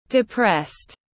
Buồn và thất vọng tiếng anh gọi là depressed, phiên âm tiếng anh đọc là /dɪˈprest/
Depressed /dɪˈprest/